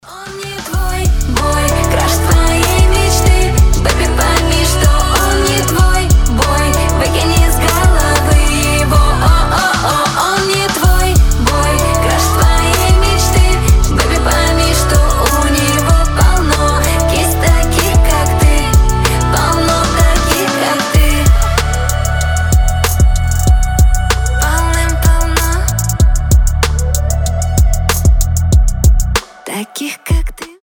• Качество: 320, Stereo
женский голос
молодежные